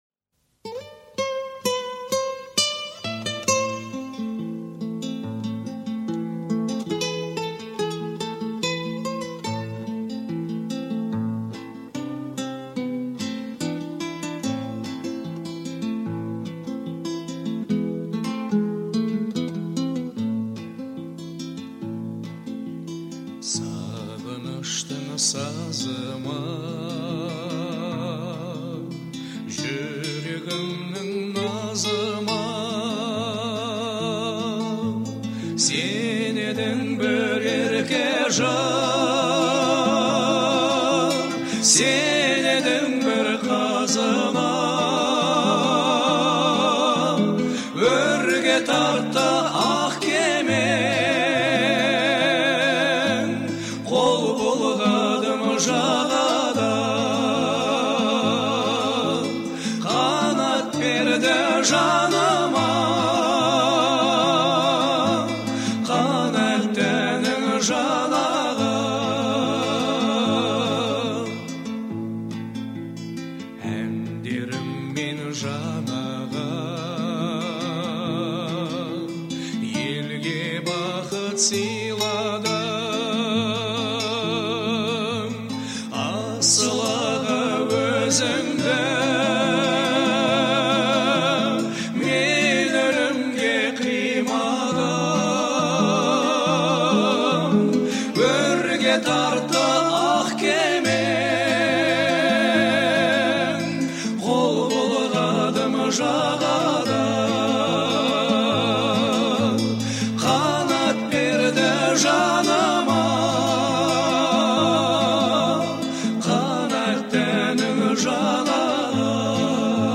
это трогательный и мелодичный трек в жанре казахского попа